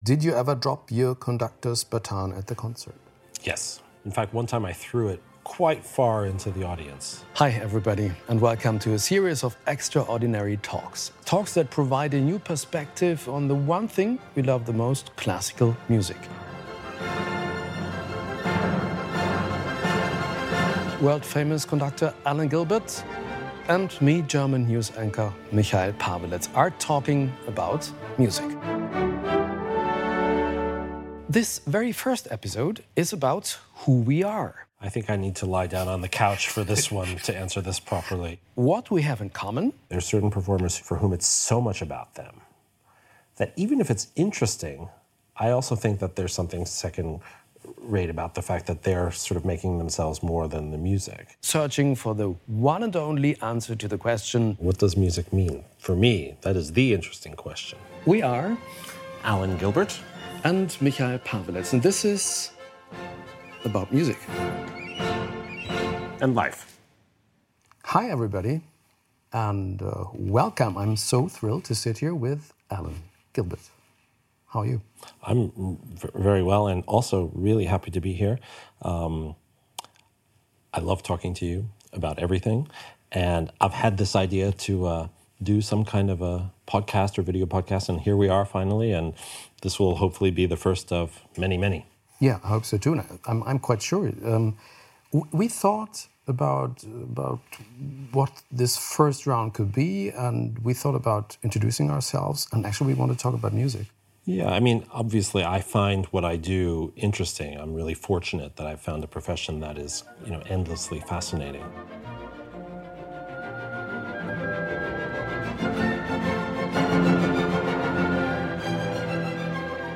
Ein Dirigentenstab, der beim Konzert ins Publikum fliegt, die Frage, ob Probenspiele fair sein können, und warum Moderieren wie Autofahren ist. Darüber sprechen Dirigent Alan Gilbert